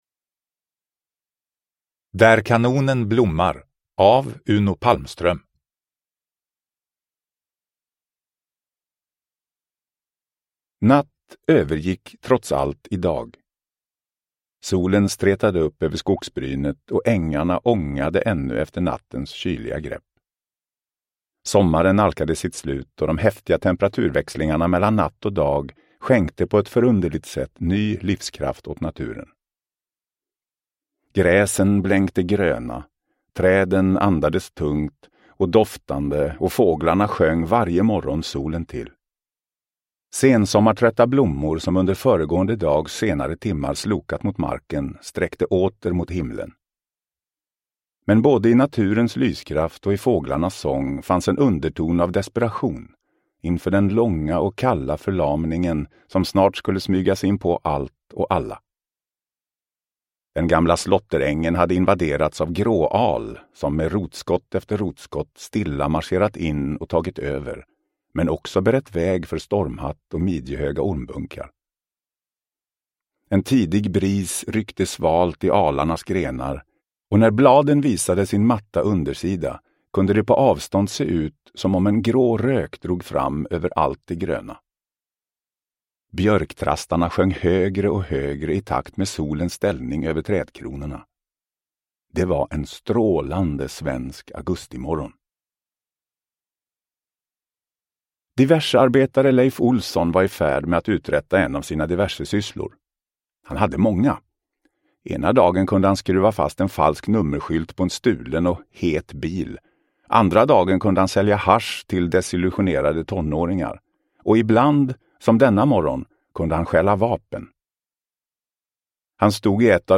Där kanonen blommar – Ljudbok – Laddas ner